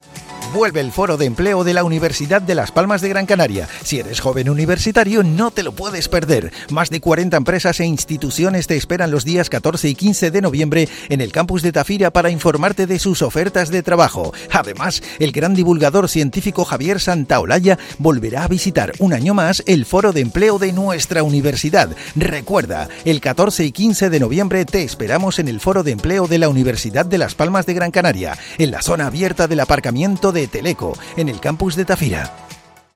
Cuña de radio